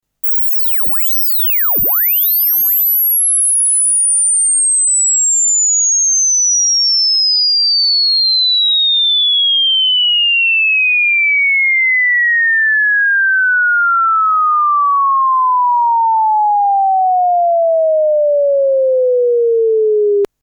スウィープ信号 -9.0dB (20kHz-400Hz; Sine; Stereo)